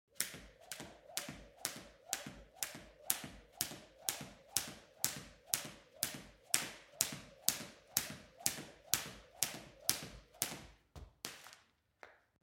دانلود آهنگ تصادف 57 از افکت صوتی حمل و نقل
جلوه های صوتی
دانلود صدای تصادف 57 از ساعد نیوز با لینک مستقیم و کیفیت بالا